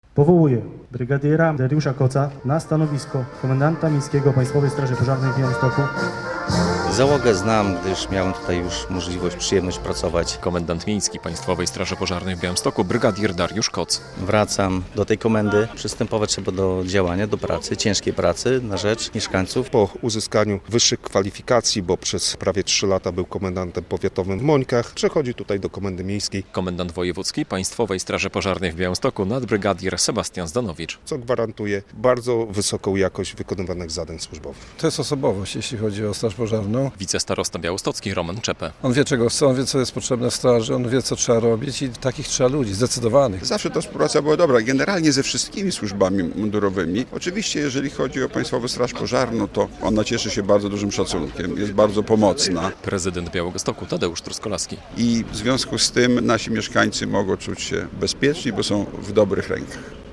Brygadier Dariusz Koc nowym komendantem PSP w Białymstoku - relacja
Uroczystość z udziałem strażaków, samorządowców i polityków zorganizowano w piątek (30.06) na placu jednostki straży pożarnej przy ul. Warszawskiej w Białymstoku.